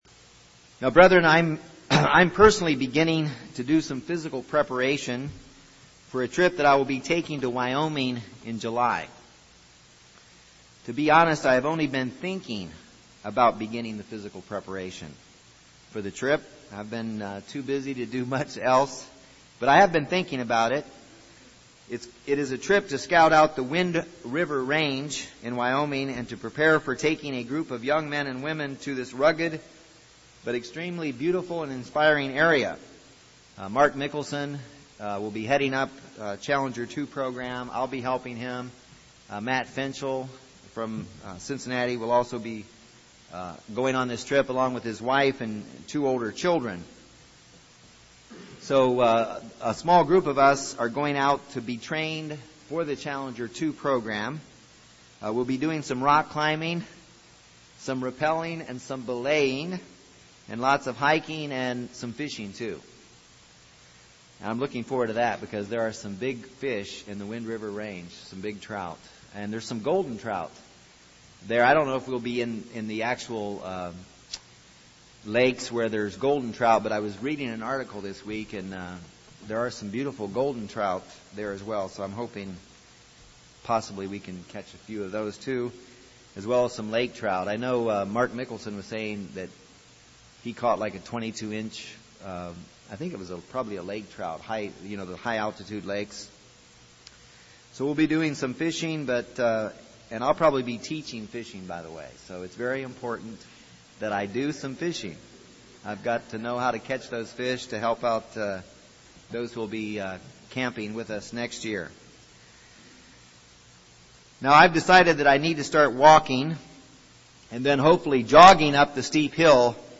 Trials are like mountains that we all need to climb from time to time. This sermon discusses seven types of trials and the key to overcoming them all.